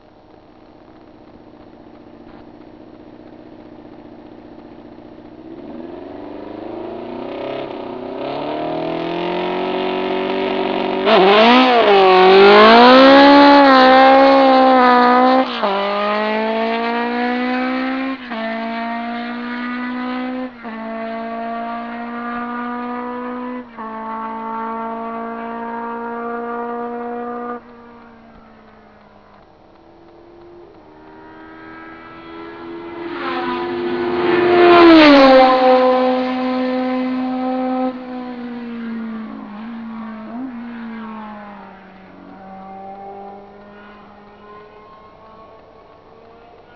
- Hubraum / Bauart des Motors : 2495 ccm / 4 Zylinder in Reihe
Stehender Start - Flat out - Vorbeifahrt Boxengasse
engine3-lotus-1.wav